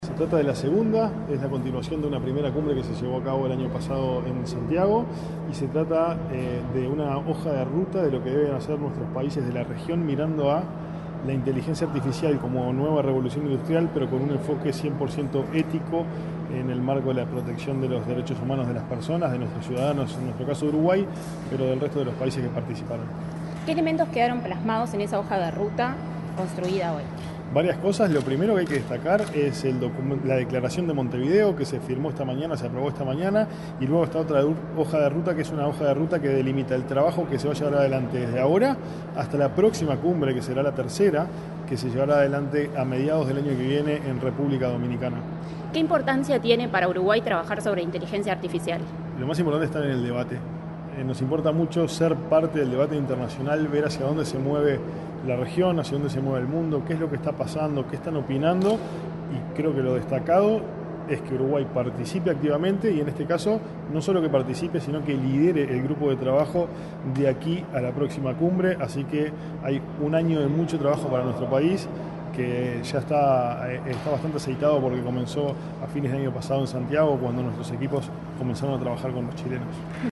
Declaraciones del director ejecutivo de Agesic, Hebert Paguas